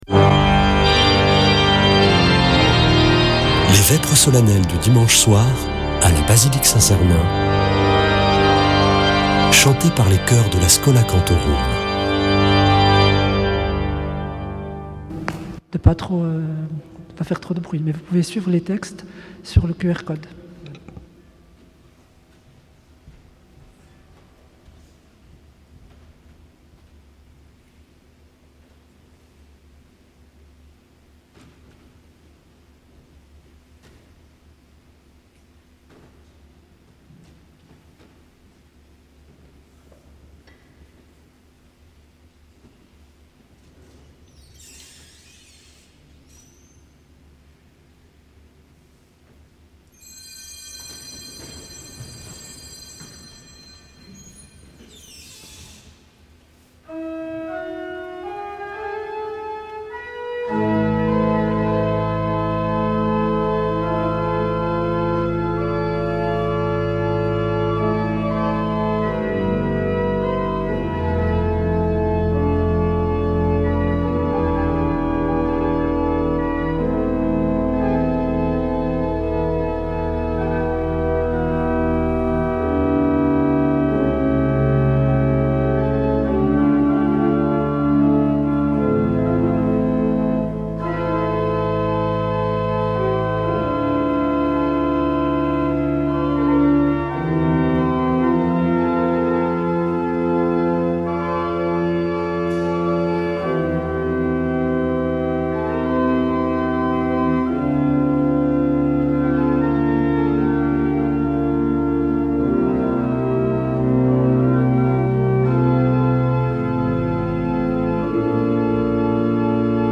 Vêpres de Saint Sernin du 08 févr.
Une émission présentée par Schola Saint Sernin Chanteurs